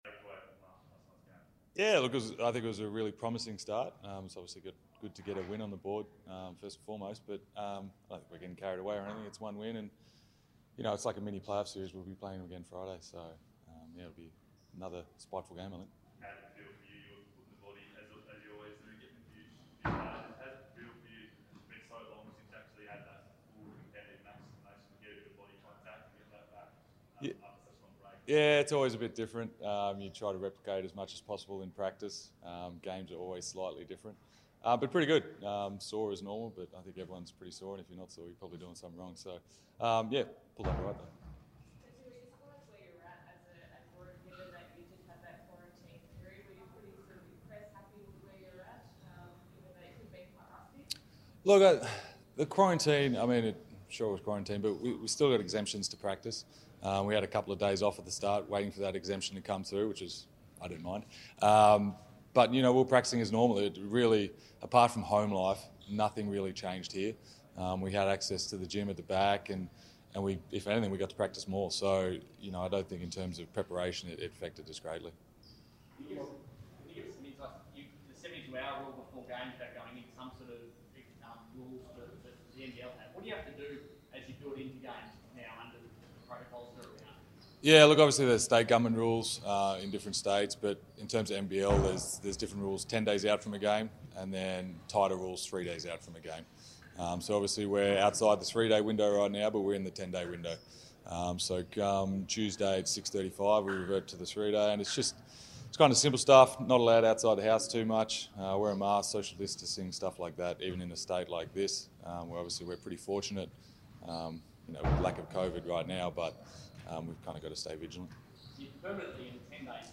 Jesse Wagstaff Press Conference - 25 January 2021